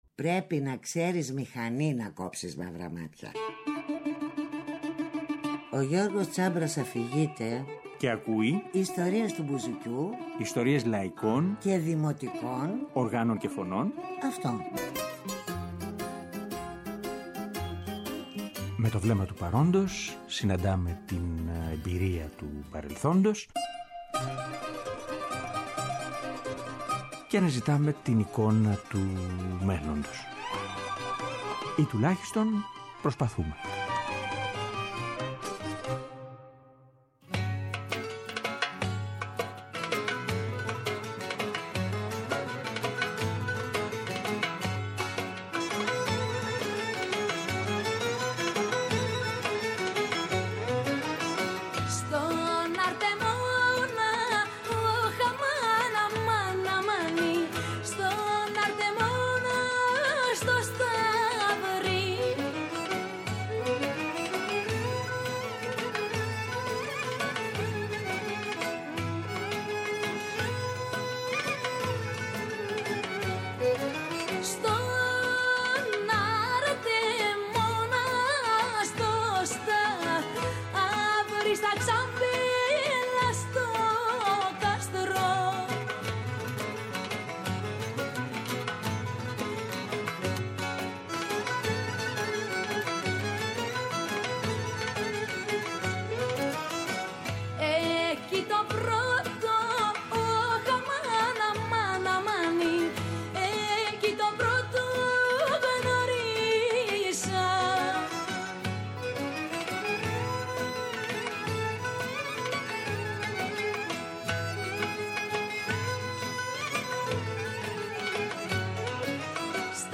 Σμυρναίικα Δημοτικά Ρεμπέτικα Λαϊκά